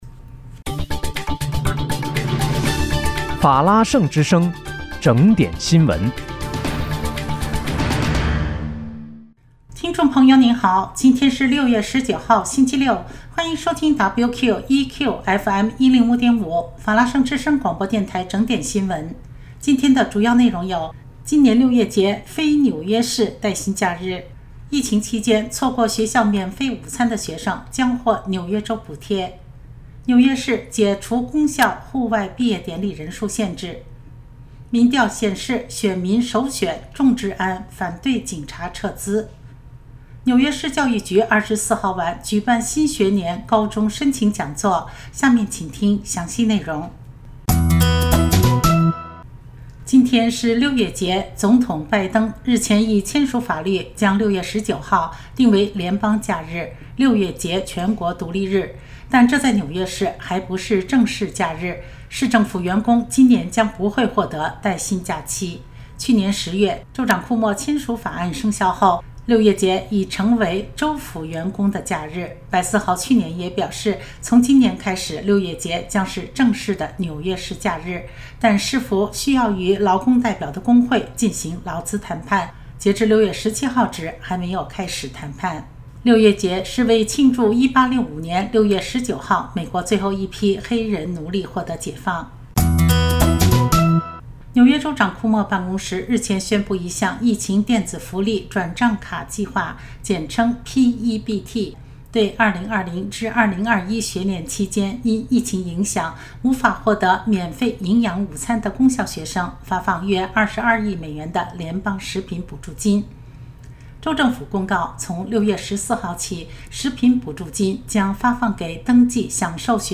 6月19日（星期六）纽约整点新闻